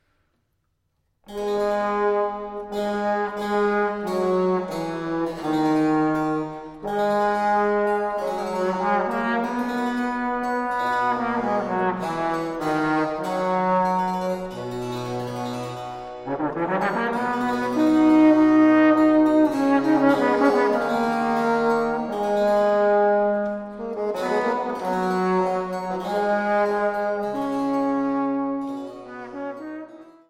Posaune